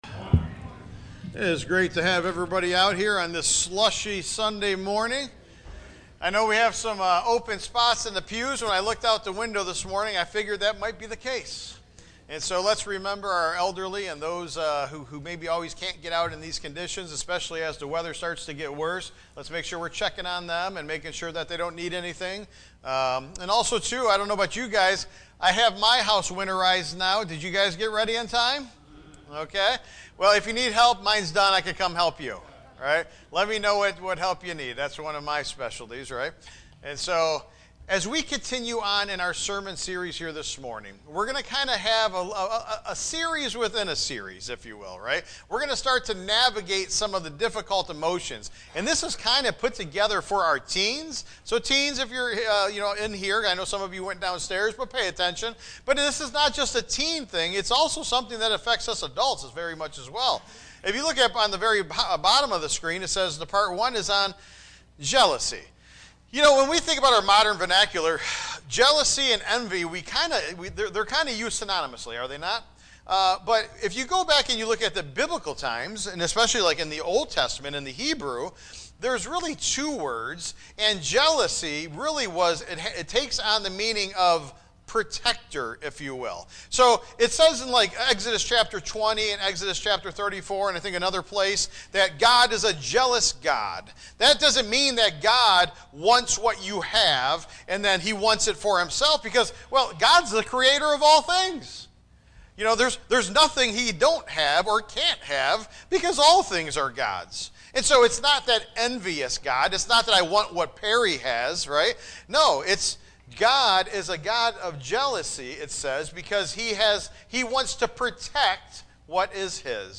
Tagged with sermon